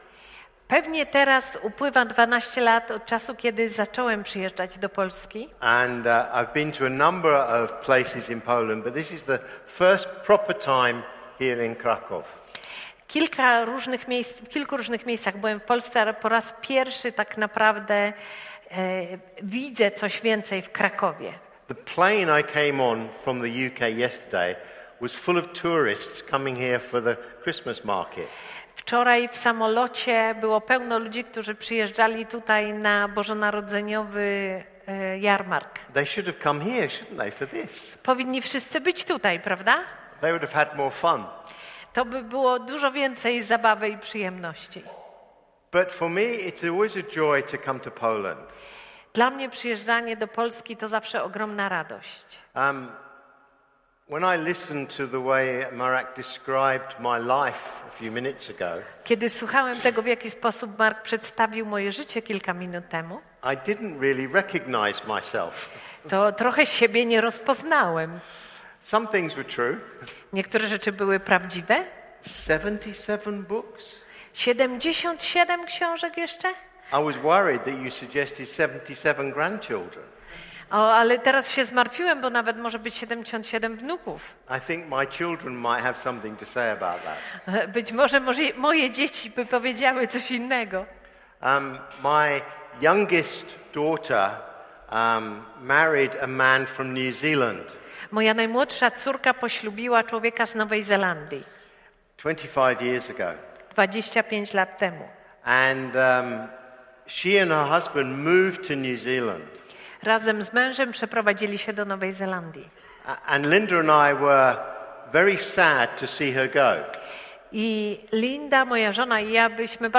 Konferencja